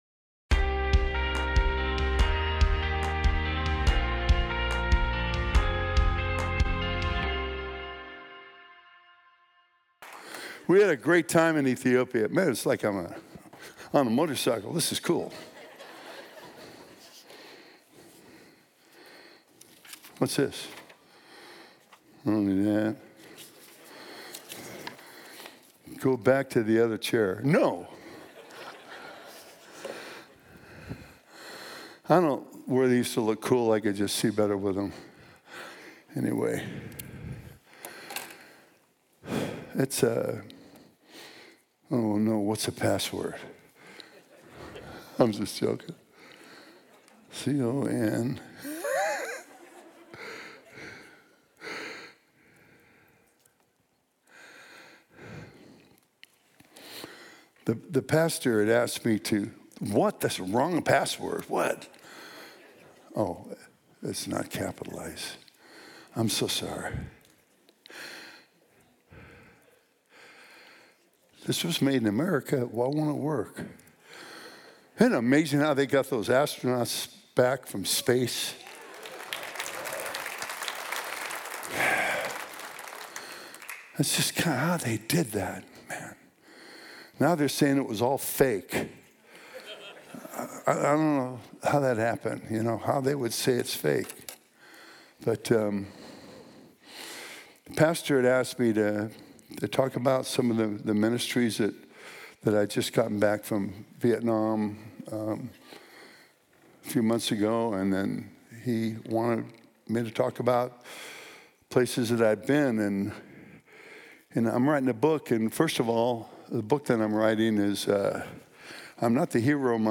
Calvary Spokane Sermon of the Week